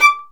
Index of /90_sSampleCDs/Roland - String Master Series/STR_Violin 1 vb/STR_Vln1 % marc